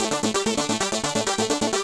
Index of /musicradar/8-bit-bonanza-samples/FM Arp Loops
CS_FMArp B_130-A.wav